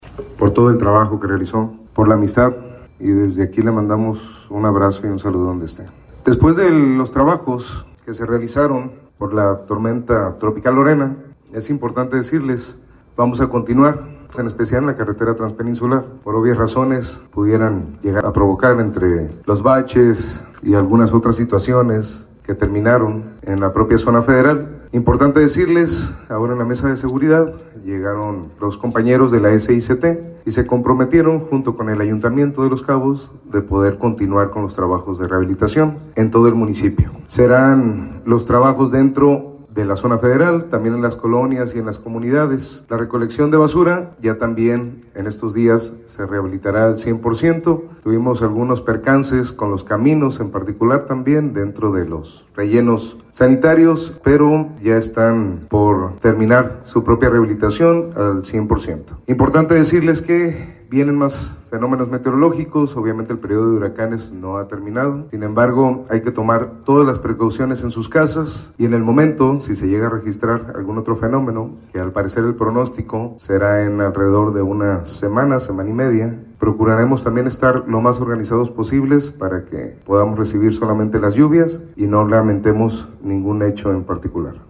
presidente municipal de Los Cabos, Christian Agúndez Gómez